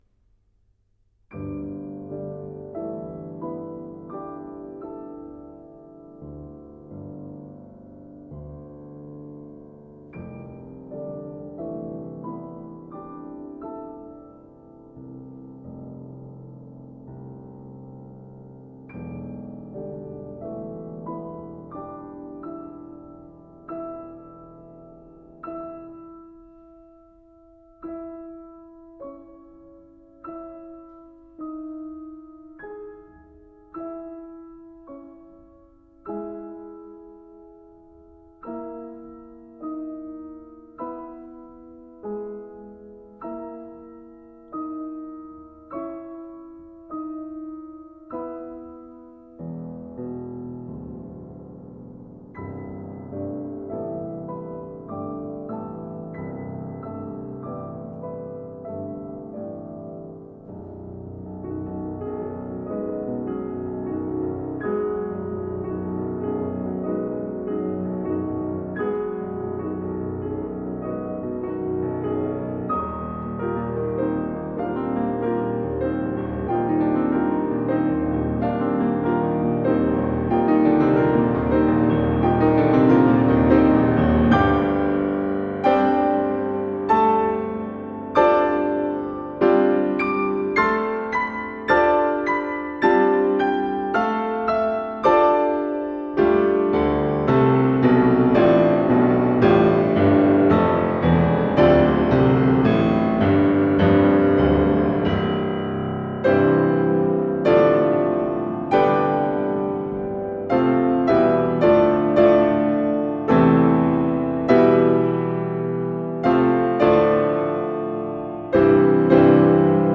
A powerful and breathtaking step up from there is musically simple yet somewhat complex for the musician.